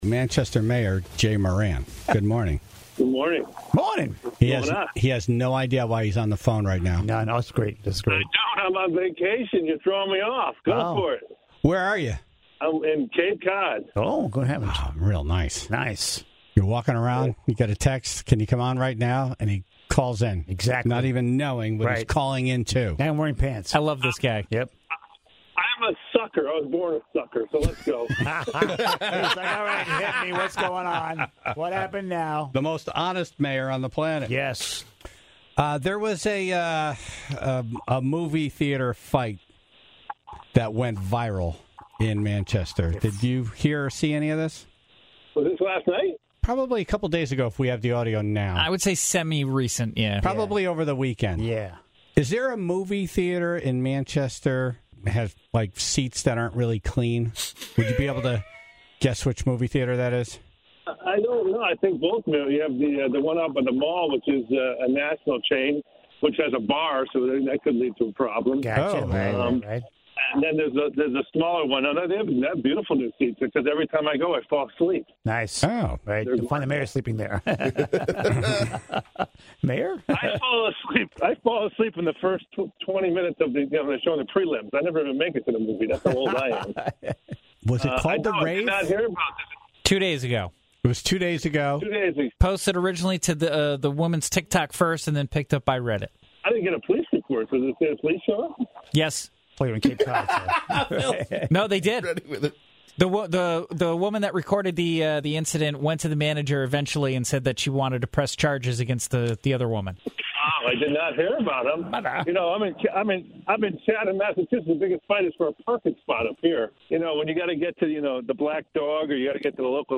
Manchester, Connecticut went viral recently, thanks to a video of two women arguing with and shoving each other in a movie theater with their children. Mayor Jay Moran was on from his vacation to address the situation.
During the calls, two roofers argued with each other about whether or not someone had literally been thrown off of a roof.